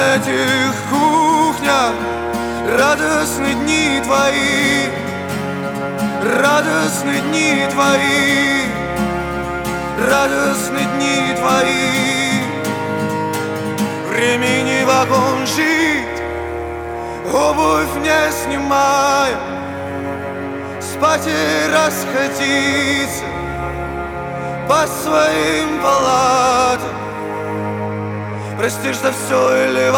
Alternative Indie Rock